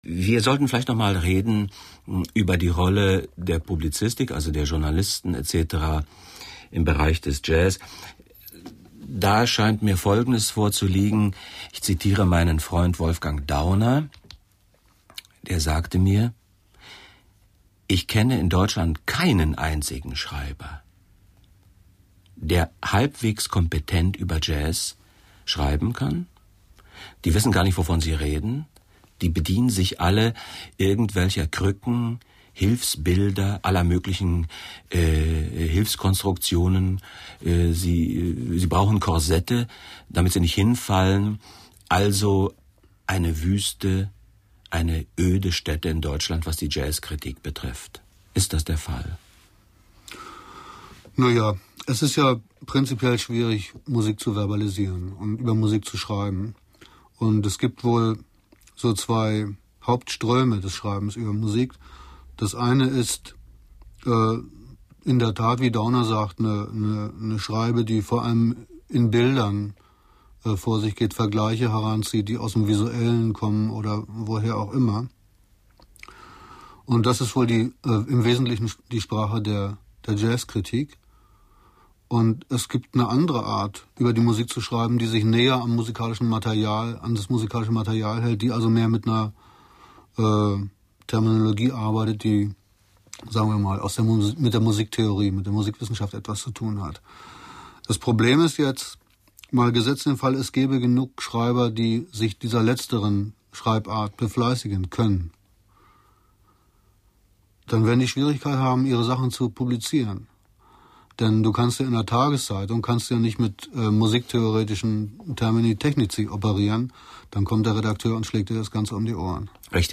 Ausschnitt aus einer Sendung des NDR. Hier geht es noch um die Publizistik, es trifft aber natürlich auf alle Formen der „Verbalisierung von Musik“ zu.